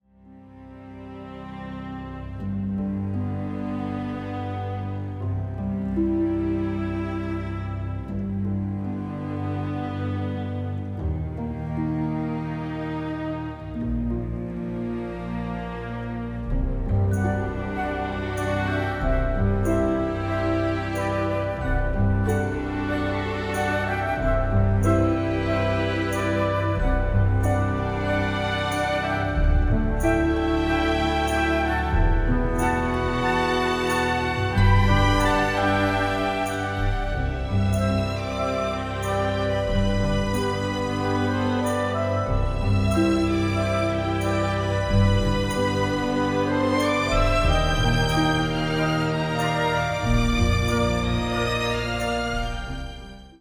mirroring reality with melancholic orchestration.